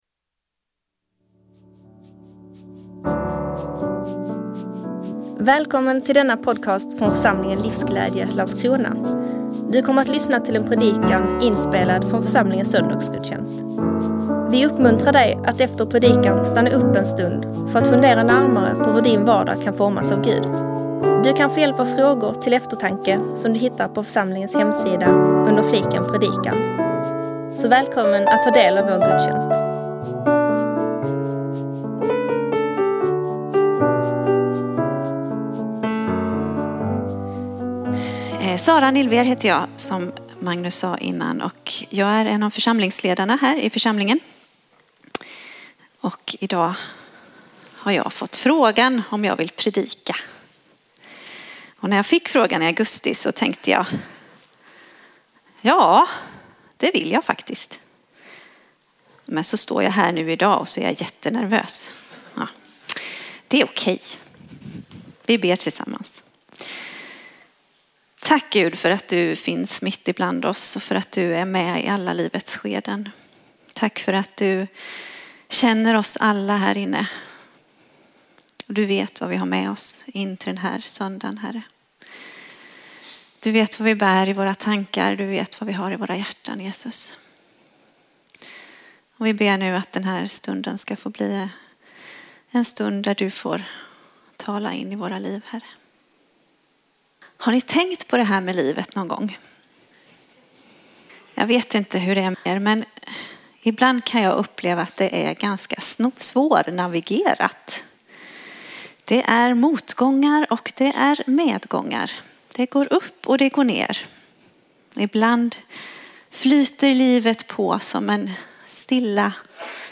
predikar om en närvarande Gud som ger oss ett levande hopp mitt i vår vardag där vi möter svårigheter.